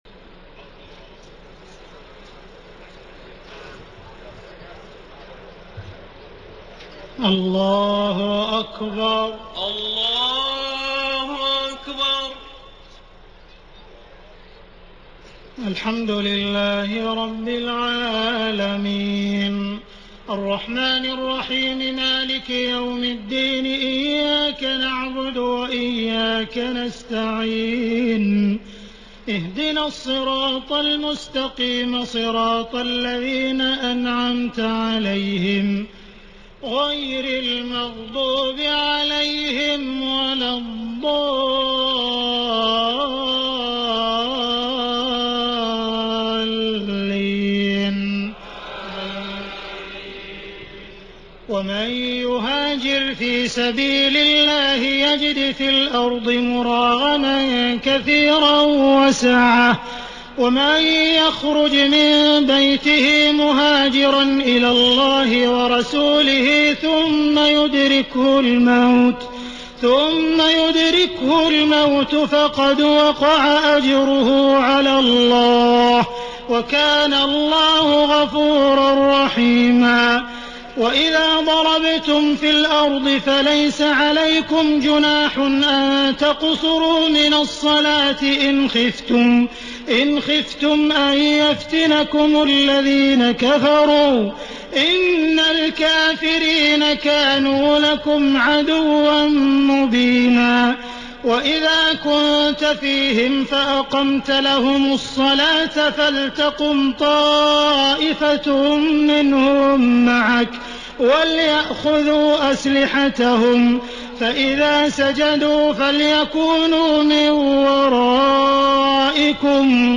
تهجد ليلة 25 رمضان 1433هـ من سورة النساء (100-147) Tahajjud 25 st night Ramadan 1433H from Surah An-Nisaa > تراويح الحرم المكي عام 1433 🕋 > التراويح - تلاوات الحرمين